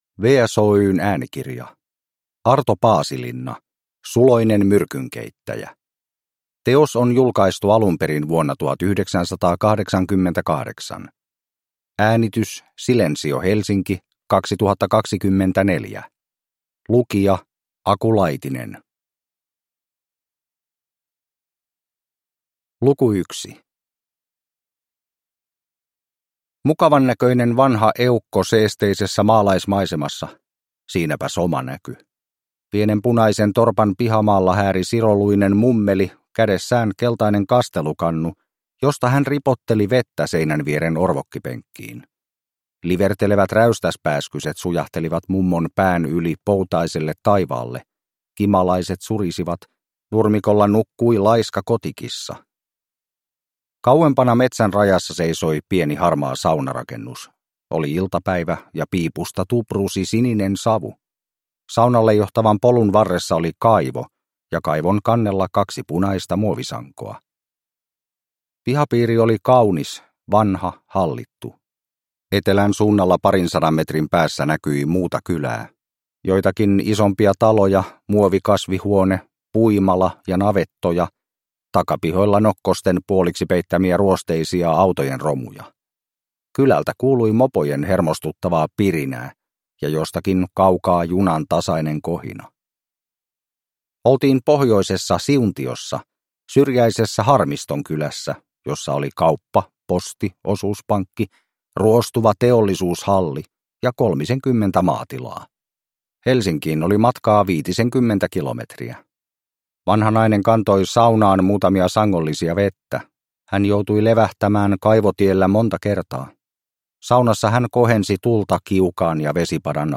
Suloinen myrkynkeittäjä – Ljudbok